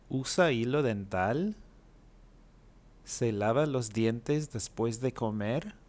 Listen to the audio clips and pay attention to the different intonations used in statements and questions.
Question-inflection.wav